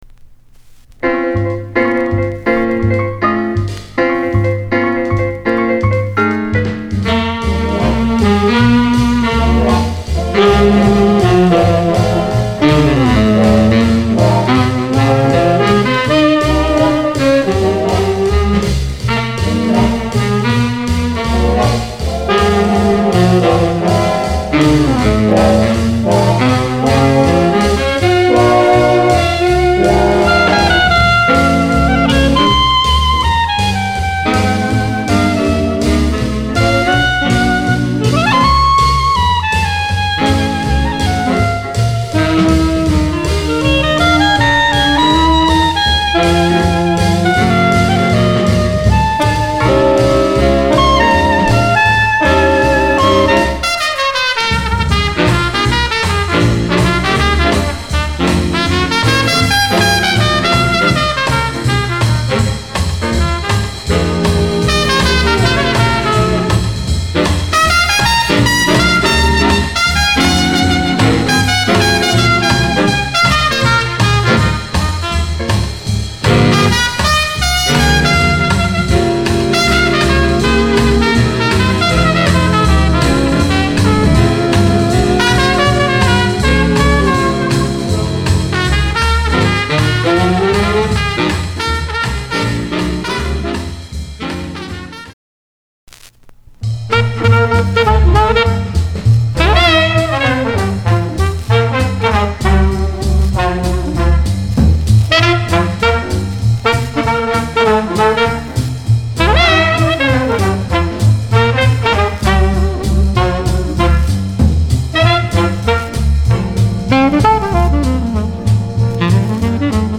discription:Mono　マルーン　溝なし